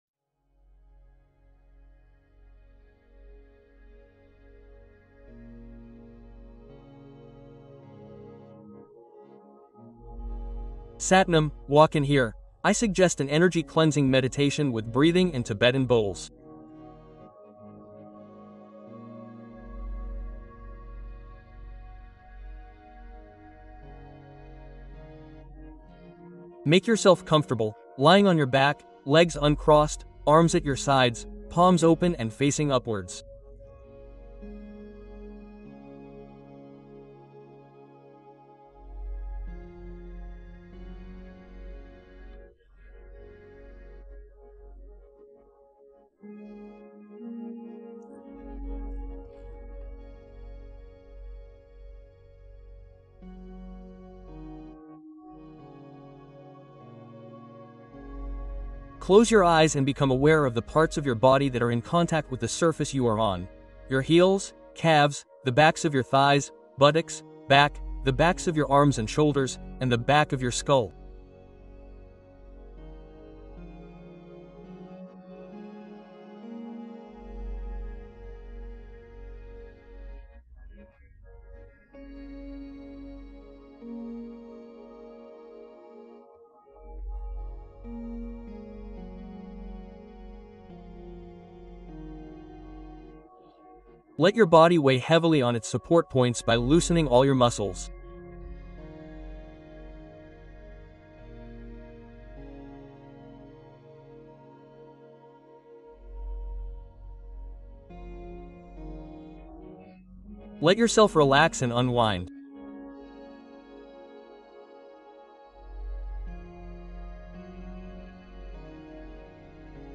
Bols tibétains : purifier les peurs et ouvrir l’énergie